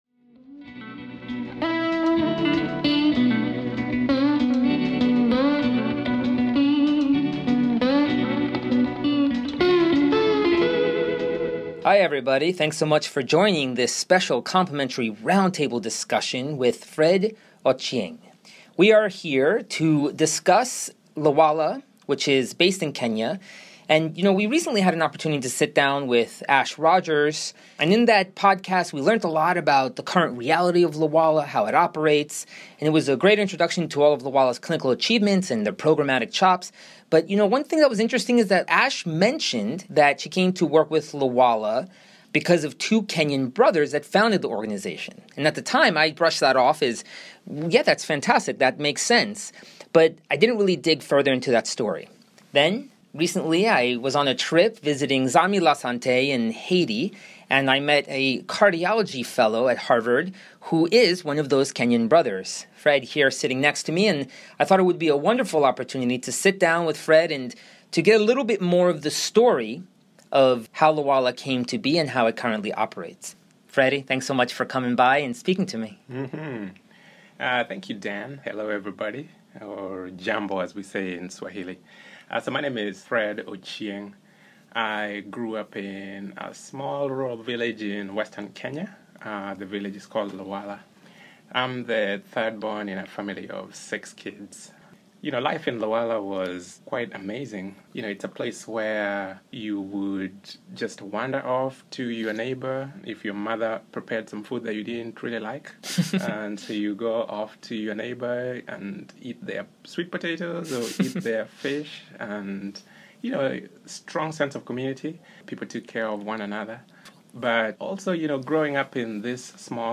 In this PIH interview